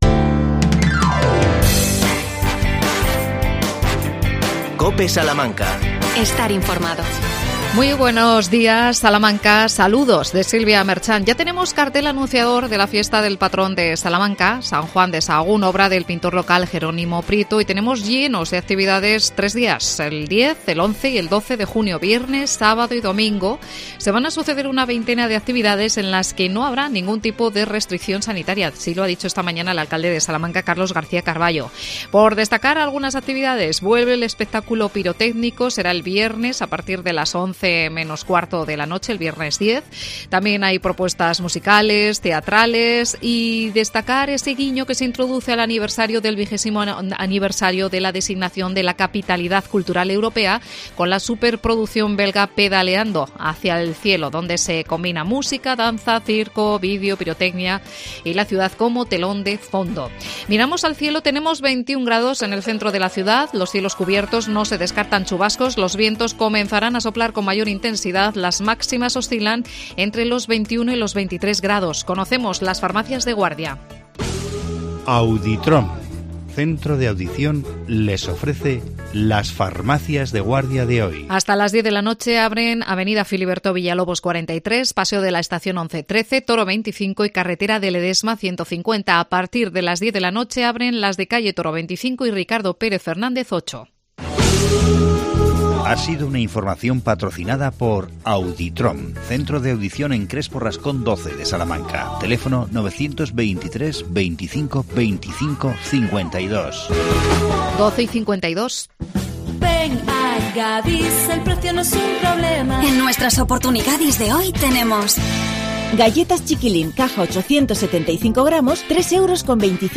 AUDIO: Llega el Festival del Siglo de Oro. Entrevistamos